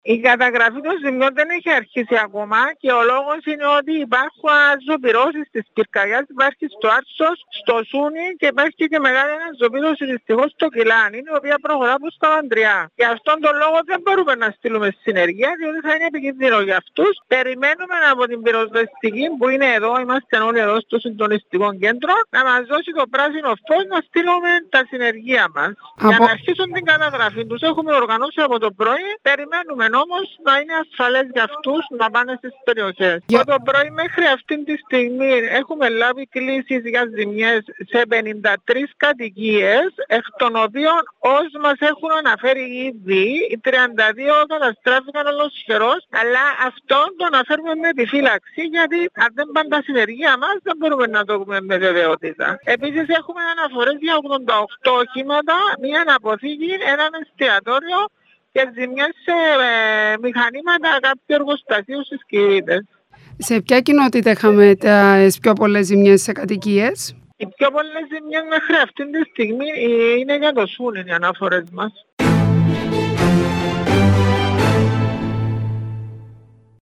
Λόγω των αναζωπυρώσεων δεν έχει ξεκινήσει ακόμα η καταγραφή των ζημιών στις πληγείσες περιοχές. Μιλώντας στο ΚΑΝΑΛΙ 6 η αναπληρώτρια Έπαρχος, Ηλέκτρα Παναγιώτου, ανέφερε ότι, από τις μέχρι στιγμής αναφορές που υπάρχουν, έχουν υποστεί ζημιές 53 κατοικίες, οι 32 από τις οποίες ολοσχερώς.